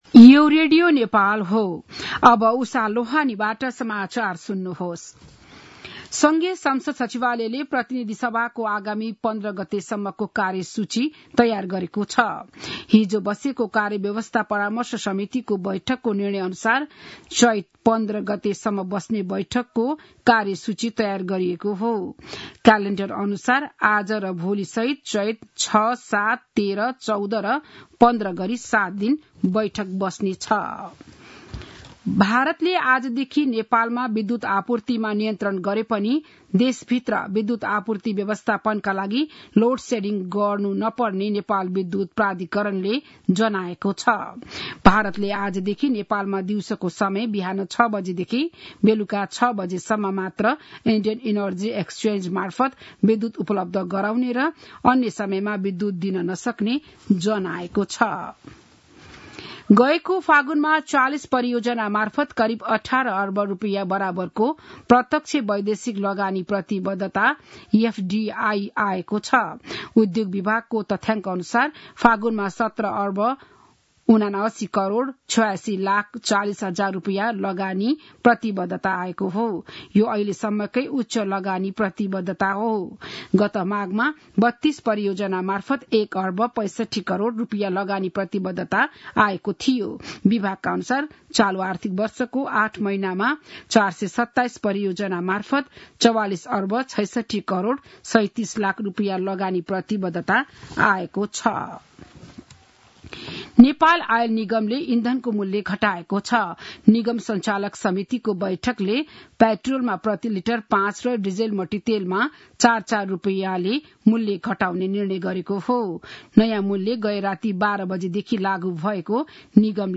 बिहान ११ बजेको नेपाली समाचार : ३ चैत , २०८१
11-am-news-1-3.mp3